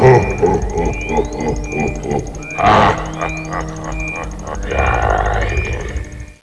The sinister laugh of Jabba the Hutt